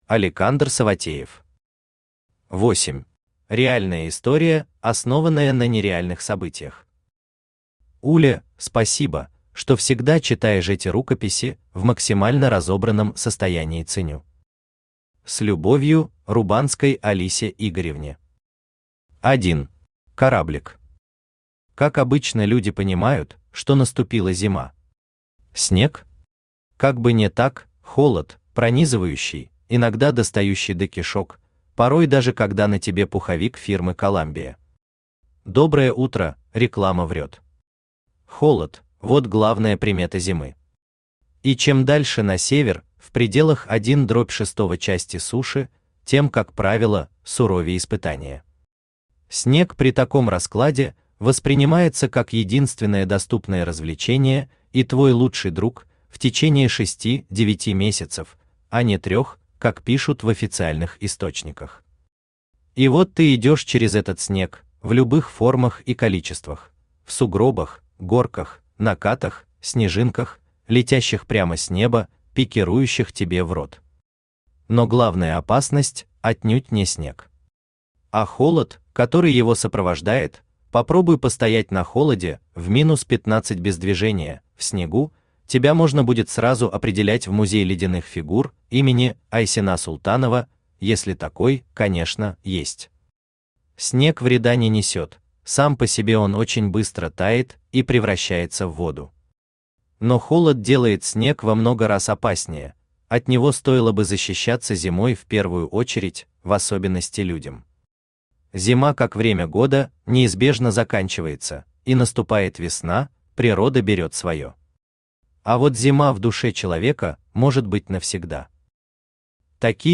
Аудиокнига Восемь | Библиотека аудиокниг
Aудиокнига Восемь Автор Алекандр Александрович Саватеев Читает аудиокнигу Авточтец ЛитРес.